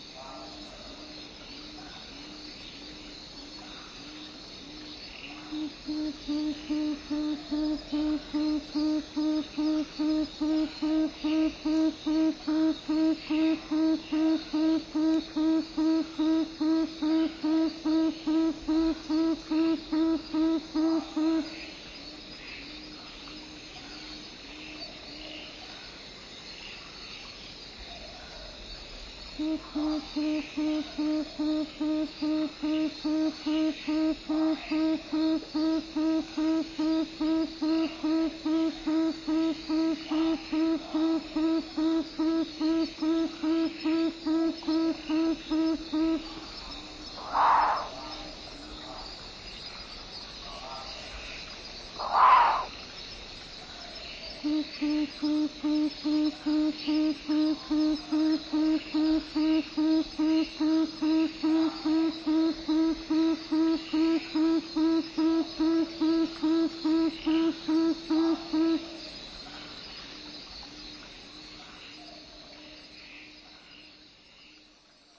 Avustralya ve Tazmanya'nın bazı sessiz gecelerinde homurdanma sesi çıkardıkları duyulabiliyor.
Esmer Baykuş (Podargus strigoides) sesi.
podargus_strigoides.mp3